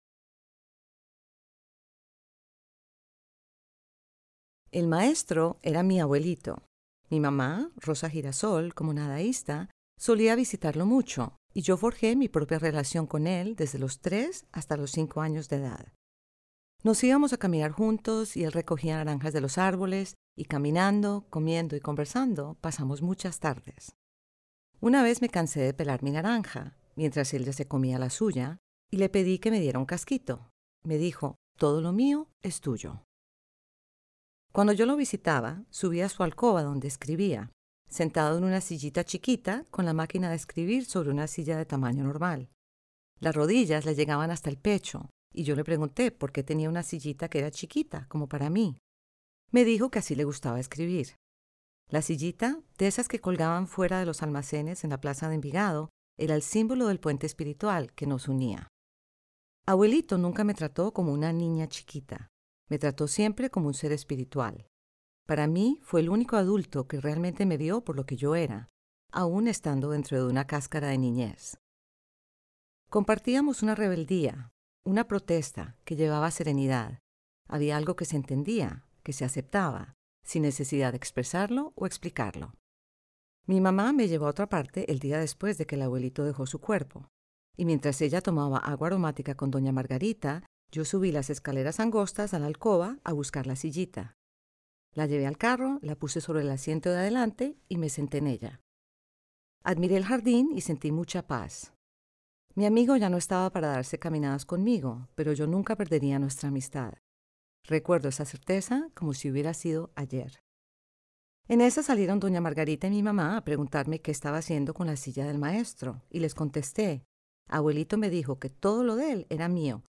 propia voz.